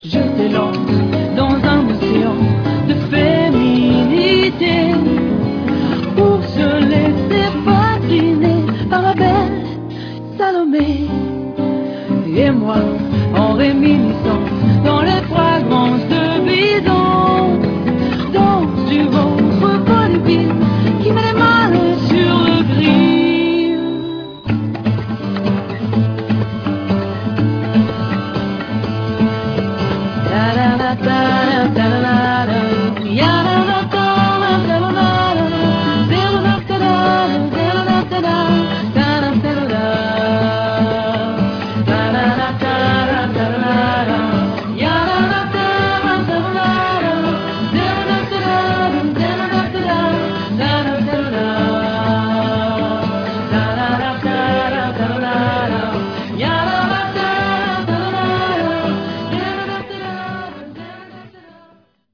13 titres réalisés au studio du Flon à Lausanne